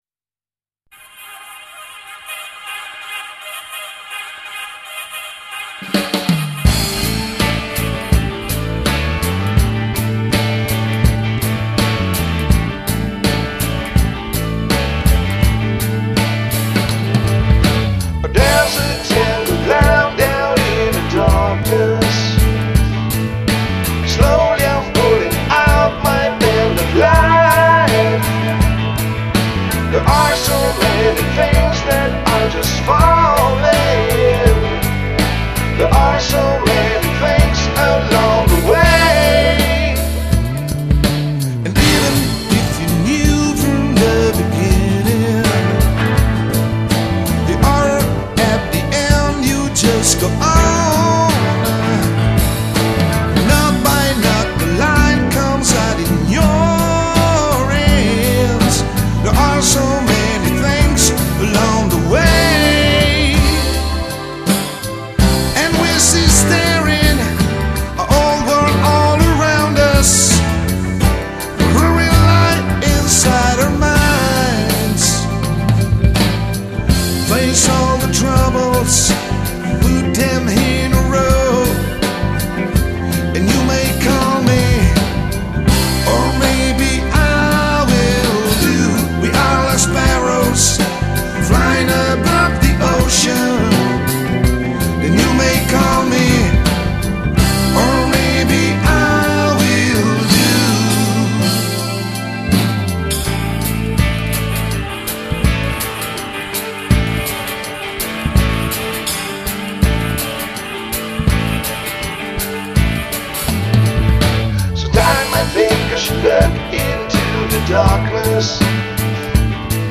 Drums
Vocals - Bass - Guitars
Keyboards and Programming
Guitar solo